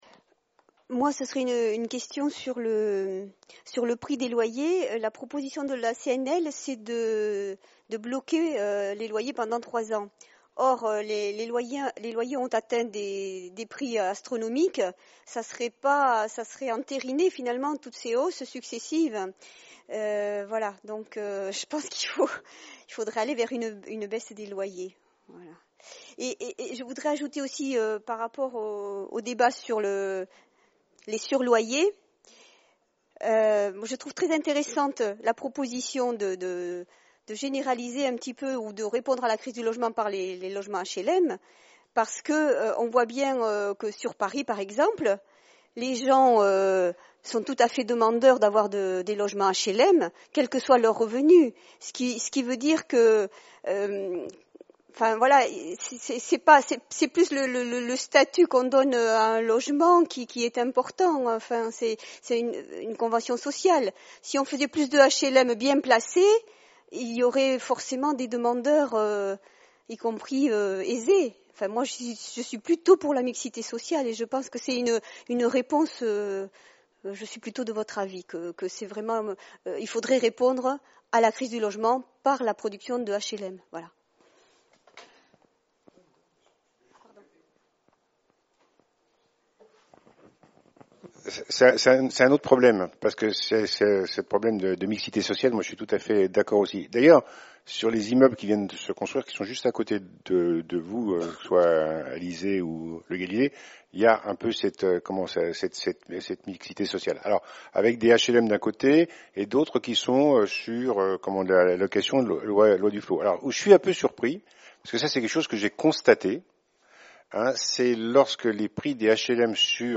Le Collectif FdG VVJ a organisé une conférence-débat sur le thème : « Le logement », le mardi 15 octobre 2013 au Centre Ravel à Vélizy.